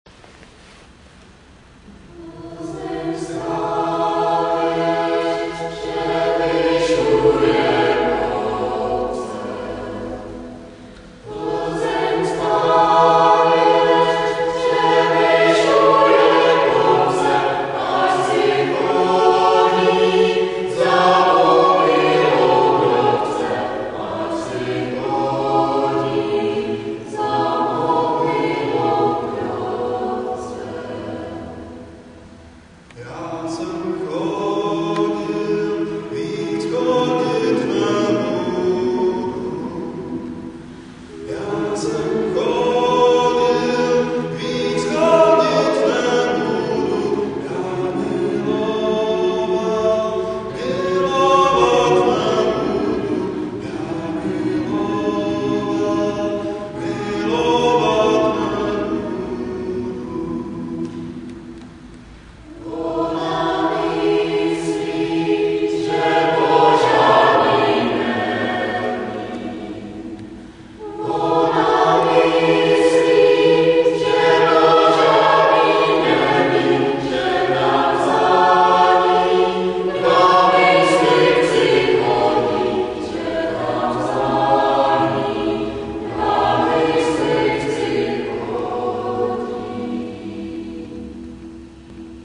Zdraví vás smíšený pěvecký sbor Praeputium.
Ukázky z koncertu (WAV):